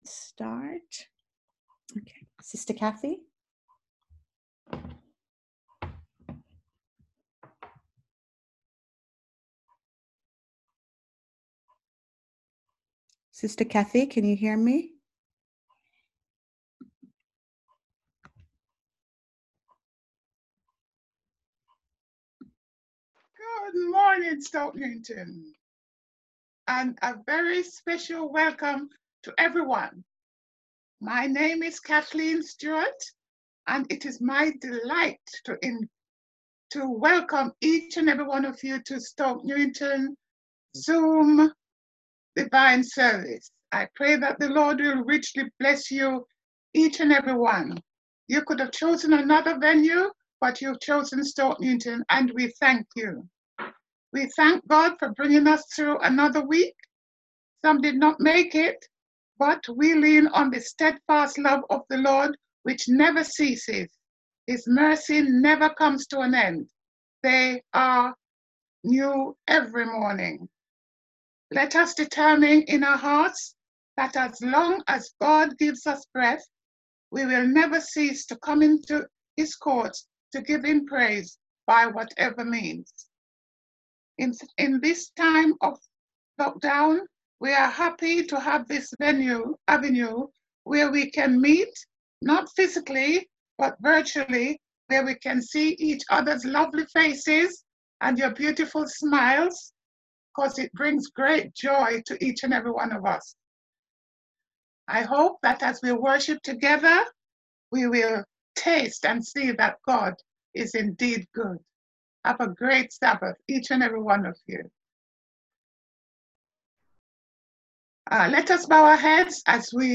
on 2024-02-14 - Sabbath Sermons